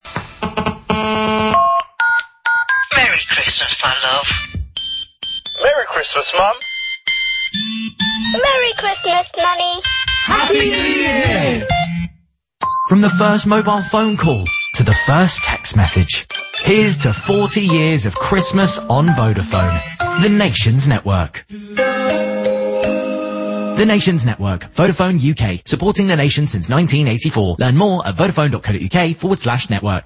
The ear-catching radio ad features a medley of iconic ringtones from years gone by, paired with simple yet powerful greetings between loved ones from Christmases past. It skilfully evokes a sense of warmth and familiarity as it takes the listener on a sentimental journey through time.